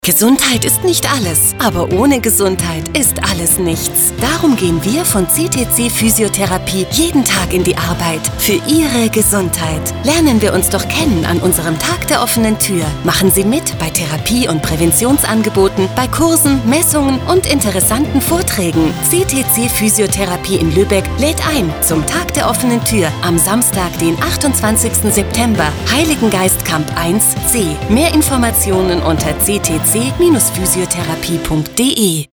Interview zum Tag der offenen Tür